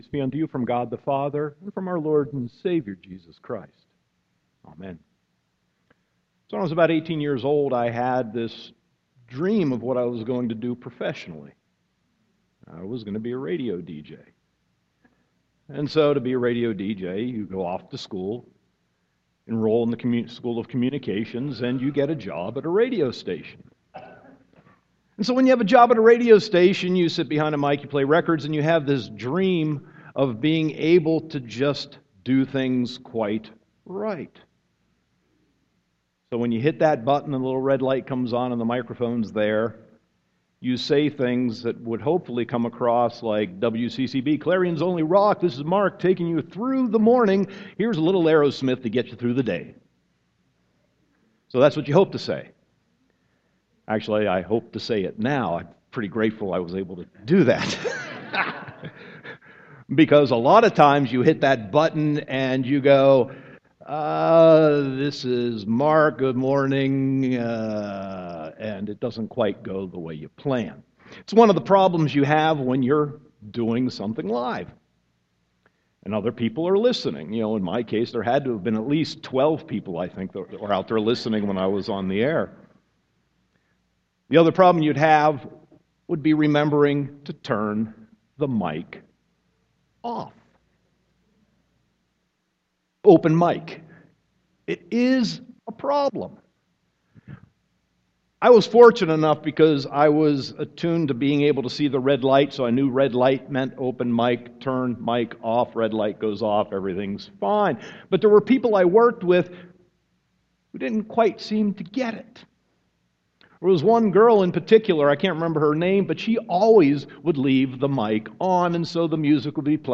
Sermon 9.13.2015